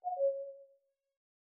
Knock Notification 14.wav